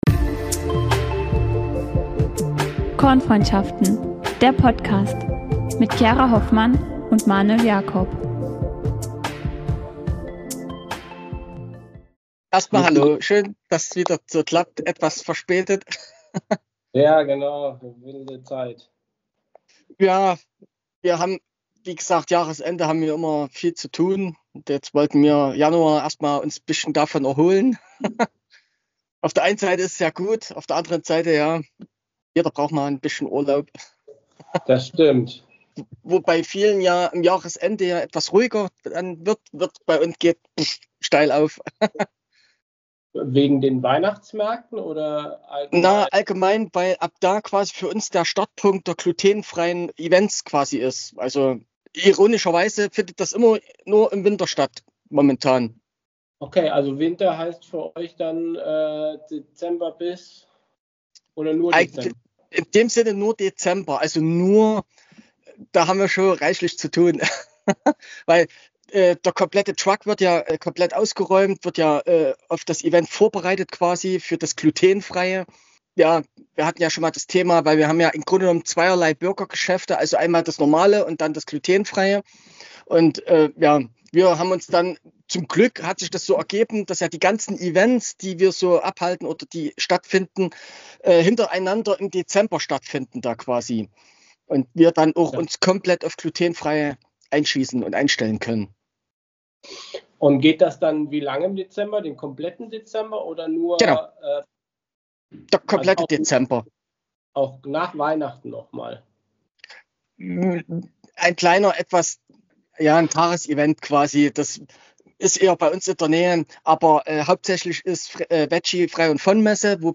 Online Interview